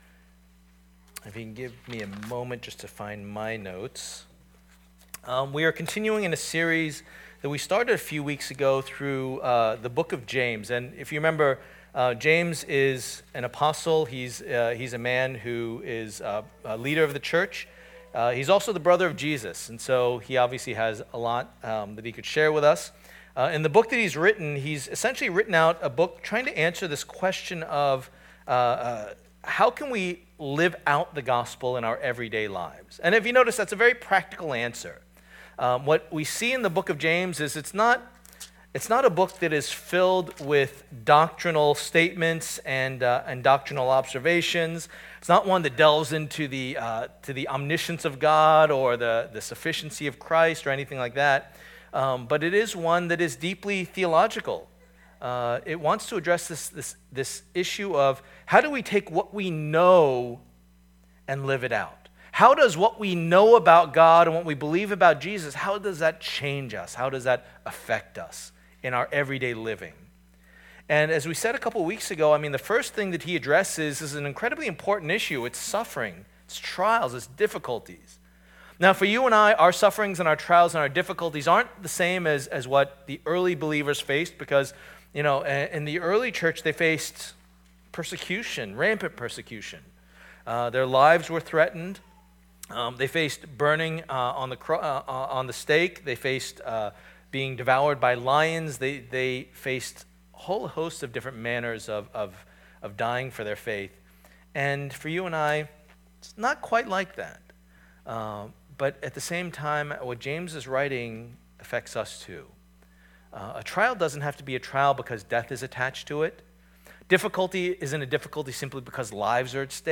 Series: Practical Faith: A Study of the Letter of James Passage: James 1:12-18 Service Type: Lord's Day